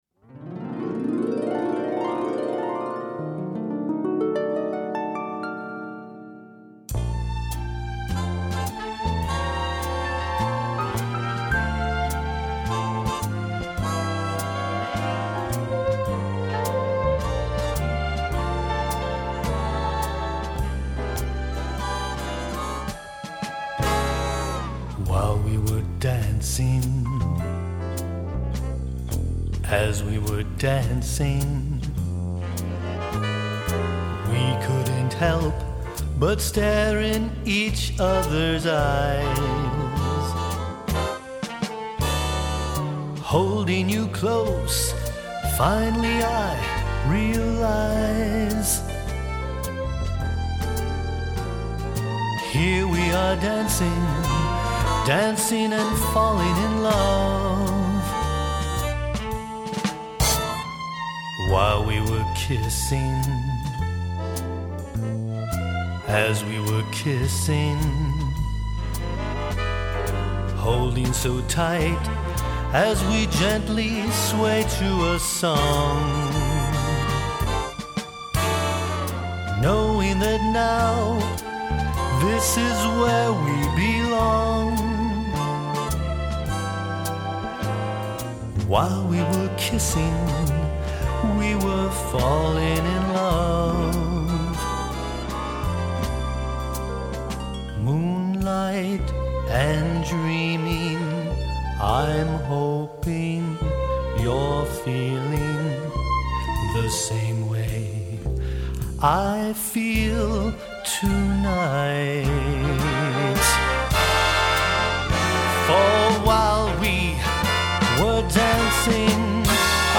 Ballad Mlvx/Big band